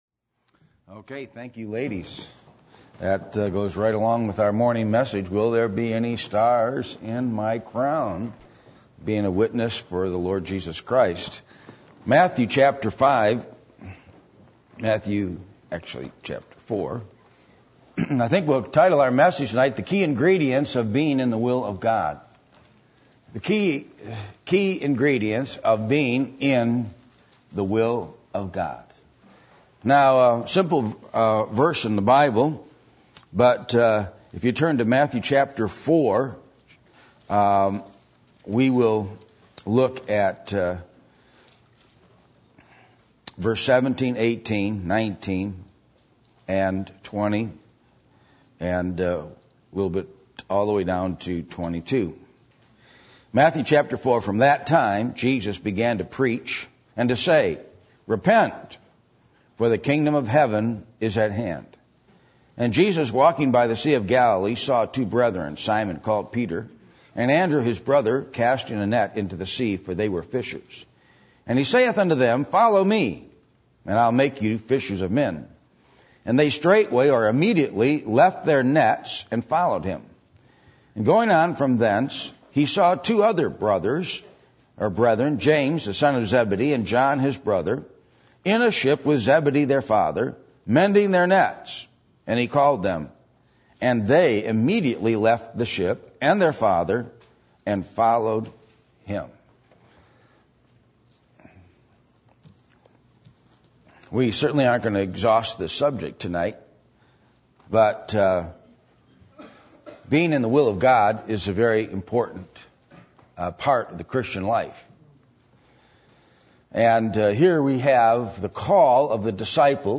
Matthew 4:17-22 Service Type: Sunday Evening %todo_render% « How Long Shall It Be?